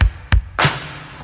• 96 Bpm 80s Pop Drum Loop A Key.wav
Free drum groove - kick tuned to the A note. Loudest frequency: 1947Hz
96-bpm-80s-pop-drum-loop-a-key-see.wav